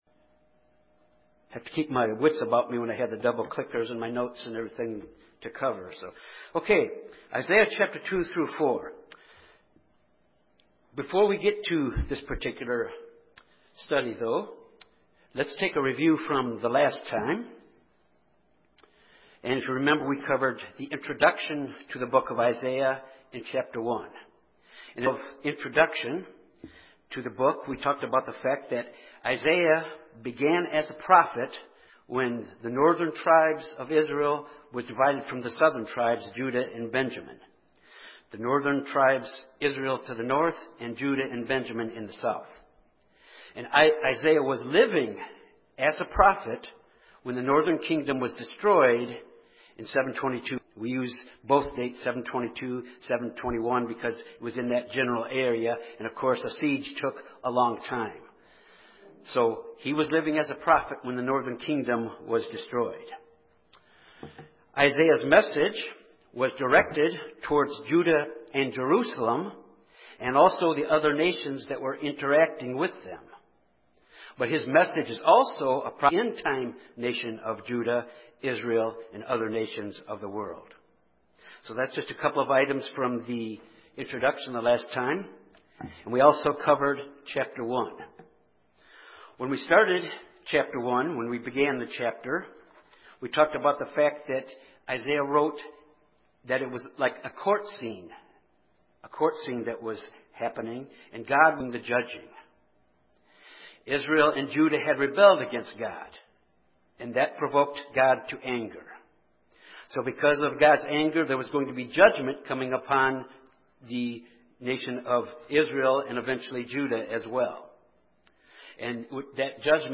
This Bible study is the continuation in the study of Isaiah covering chapters 2-4.